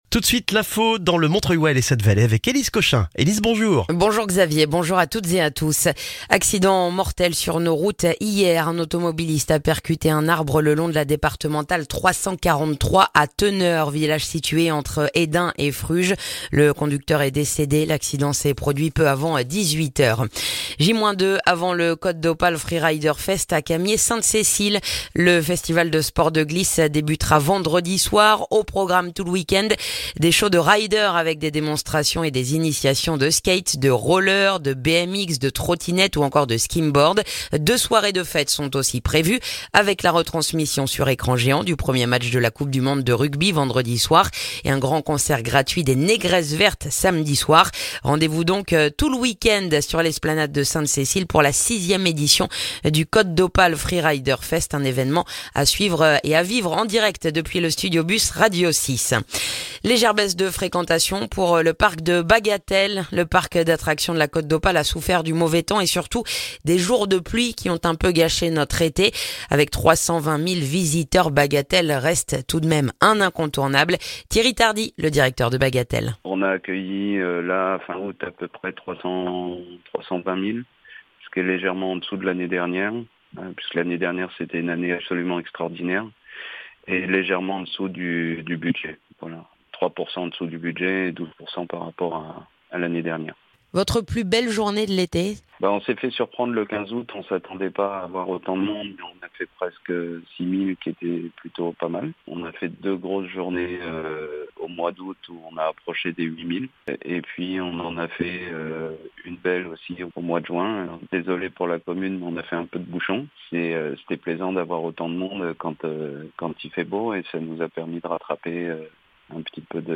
Le journal du mercredi 6 septembre dans le montreuillois